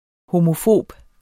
Udtale [ homoˈfoˀb ]